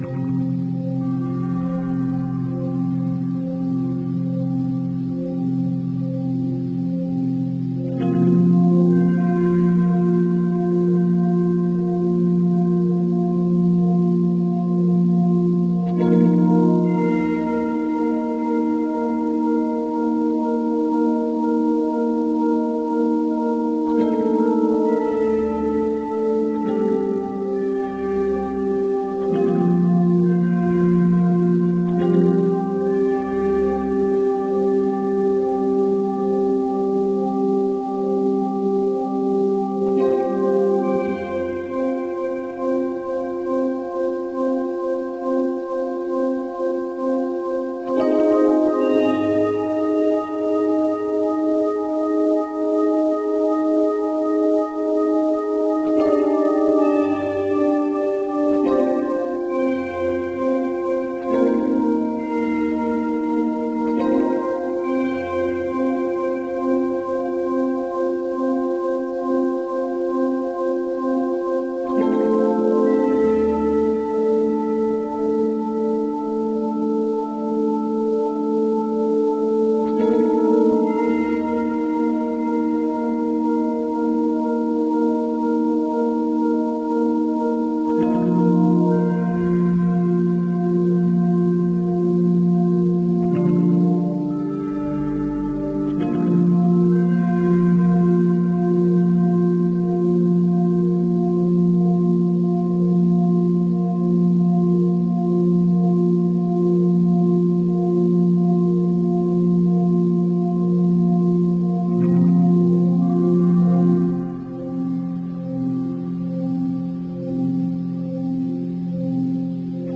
ambient.wav